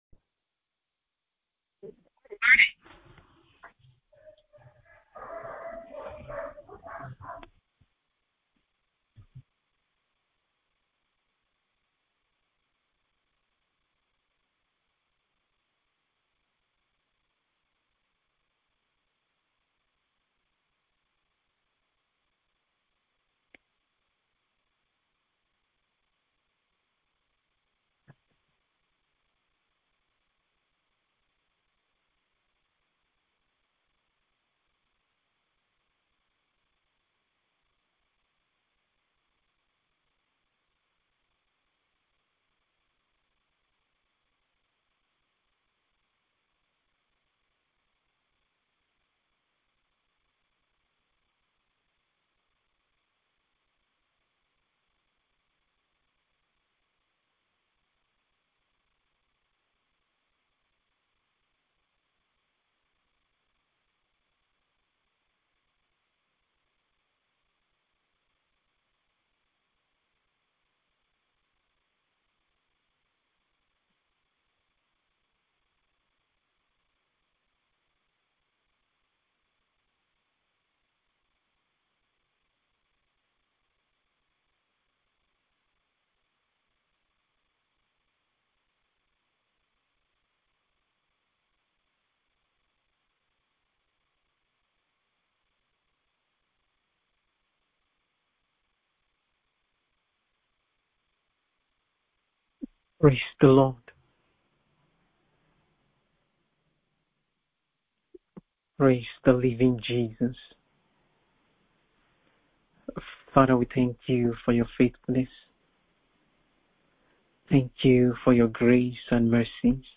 BIBLE STUDY CLASS